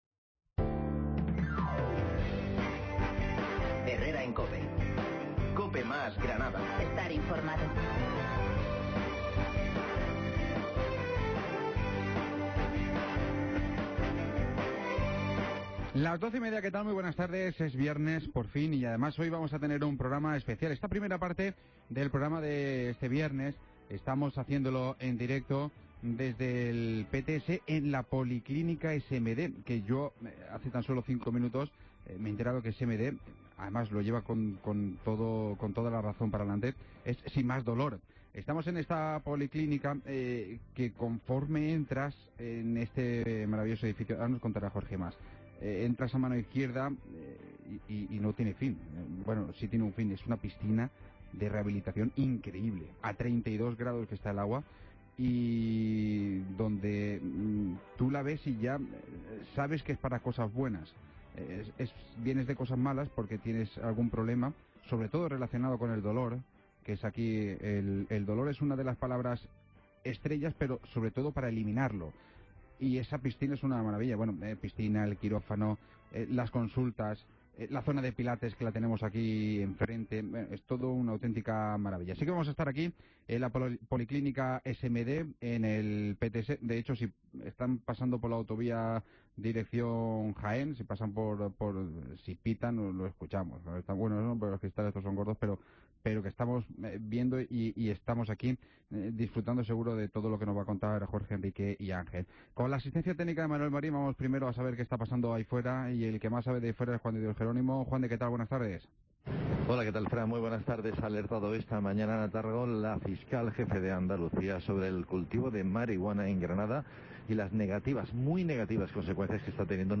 Programa especial desde Policlínica SMD